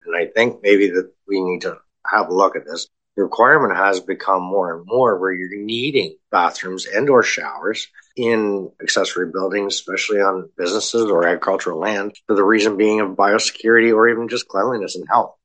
That’s following a discussion at council’s meeting last Tuesday (February 20th).
Councillor Harris suggested that perhaps they needed to reevaluate the bylaw that had caused the planner to make this reccomendation, as they didn’t consider it compatible with the needs of many businesses in Bluewater.
february-20-bw-council-meeting-outbuliding-plumbing-bylaw-harris.mp3